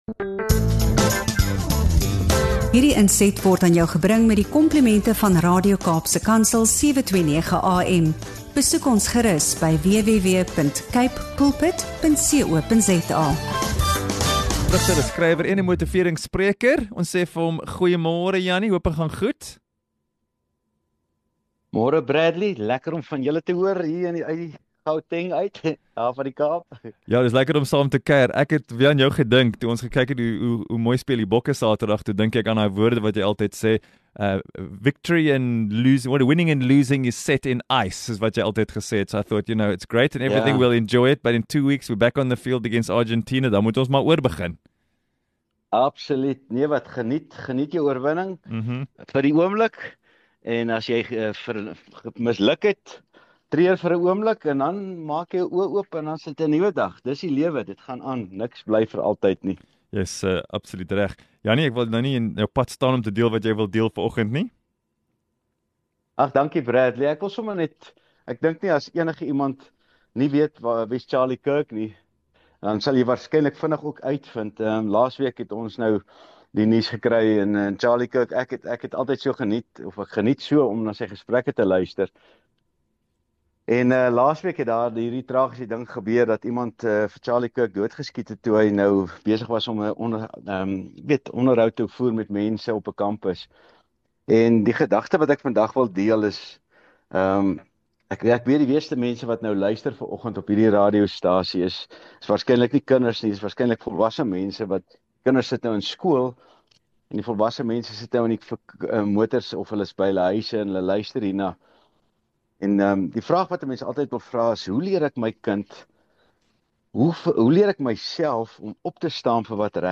In hierdie insiggewende onderhoud